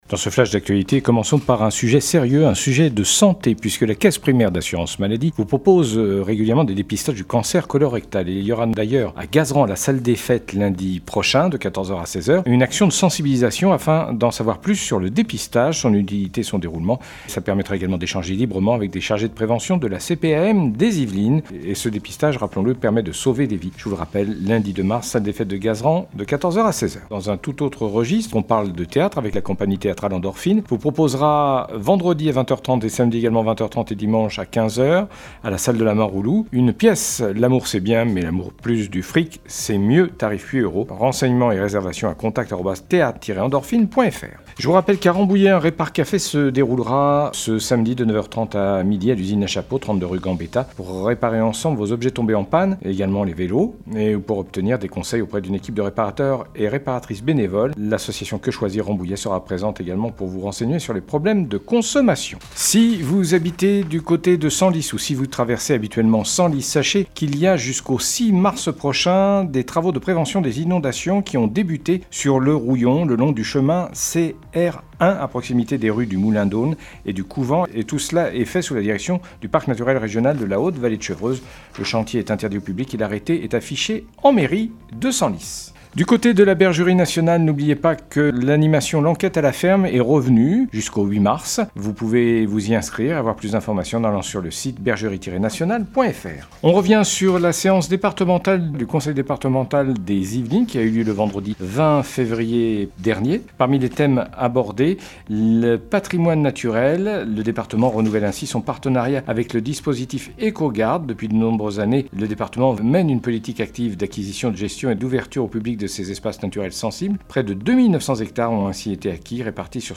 Le journal local du jeudi 26 février 2026